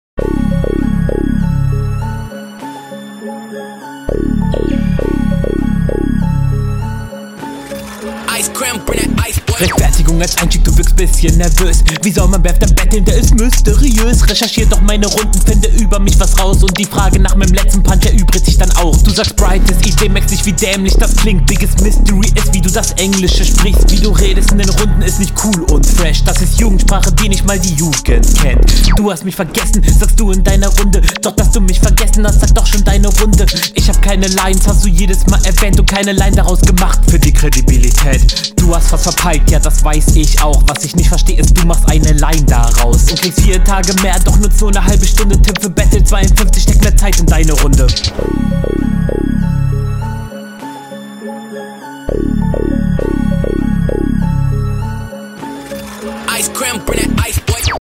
Flowlich auch etwas gehetzt, aber weniger störend, dafür passt der Stimmeinsatz nicht so zum Biet.